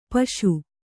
♪ paśu